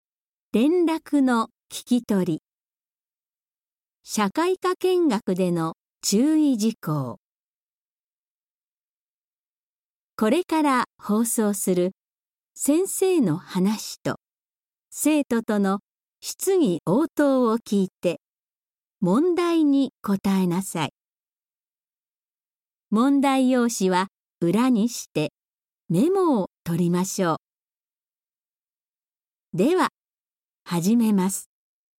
聞き取り問題
※サンプルとして、過去の問題音声を掲載しております。